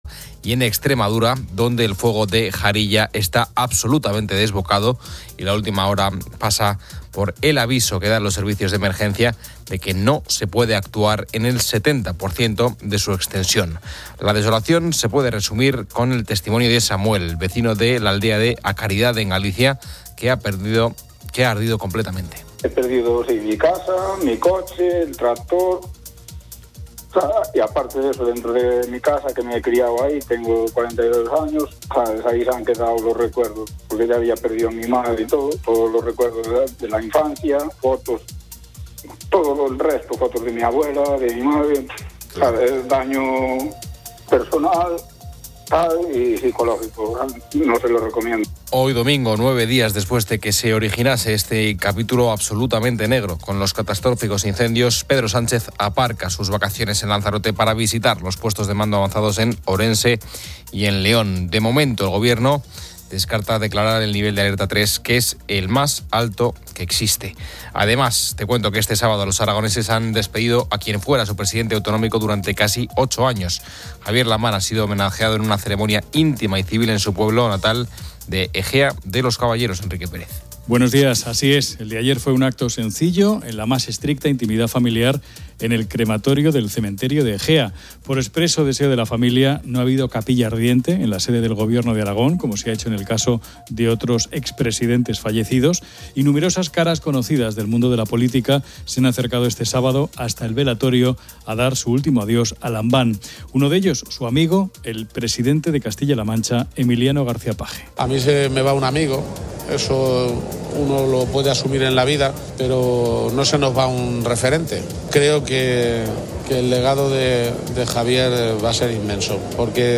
El audio cubre varias noticias y un programa de radio. Se informa sobre los incendios forestales en Extremadura, destacando el de Jarilla donde el 70% está inoperable, y la intervención del presidente Pedro Sánchez.
Un invitado especial, el músico José María Guzmán, comparte detalles sobre su larga trayectoria.